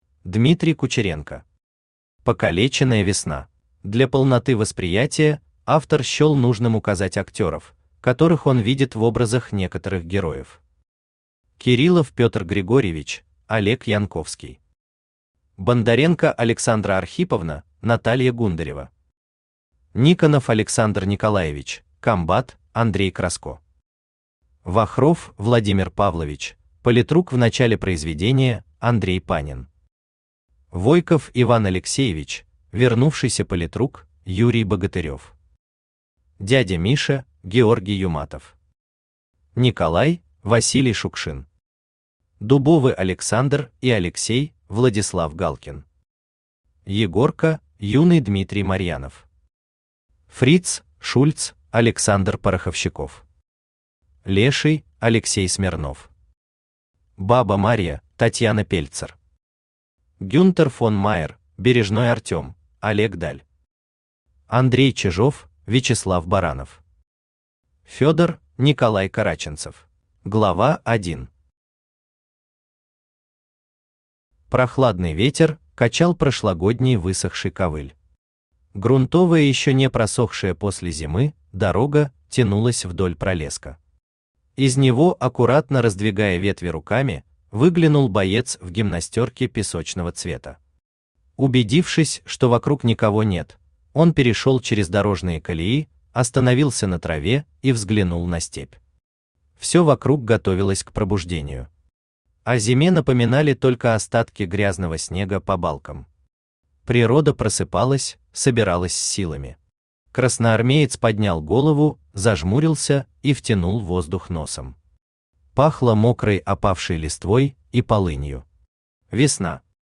Aудиокнига Покалеченная весна Автор Дмитрий Алексеевич Кучеренко Читает аудиокнигу Авточтец ЛитРес.